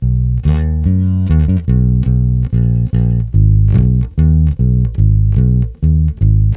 Twisting 2Nite 2 Bass-B.wav